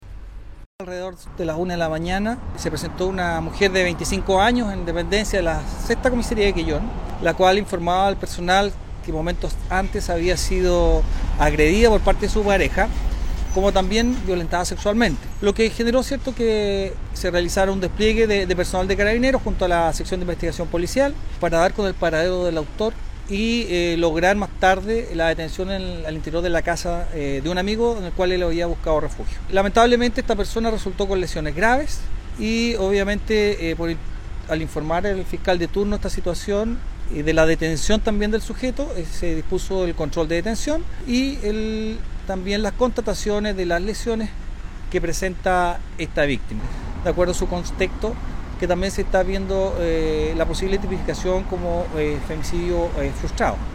Las lesiones de la mujer son graves, señaló el oficial de la policía uniformada.